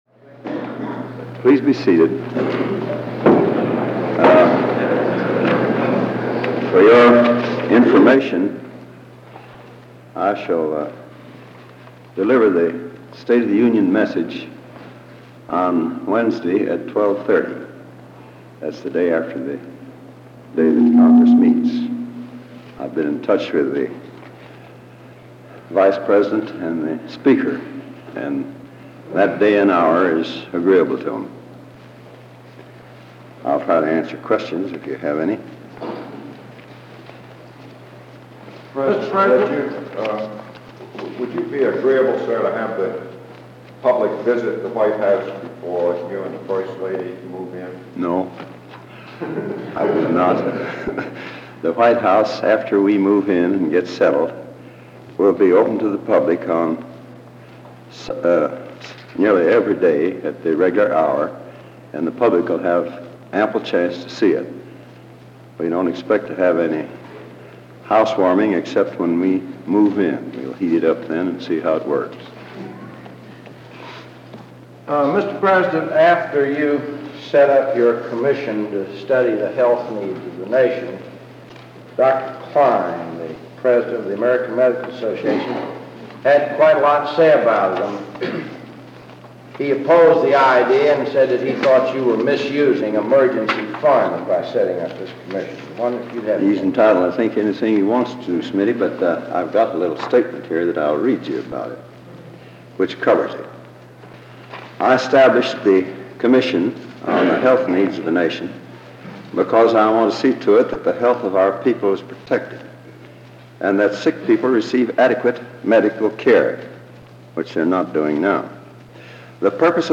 President Truman held a press conference, this January 3rd in 1952.
Simple, direct; no frills.
Truman-Press-Conf-Jan-3-1952.mp3